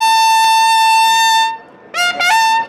Toque de corneta 3
aerófono
castrense
corneta
llamada
metal